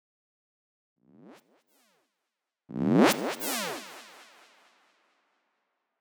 FX
Zap.wav